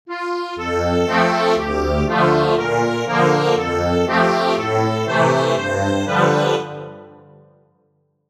Easy accordion arrangement plus lyrics.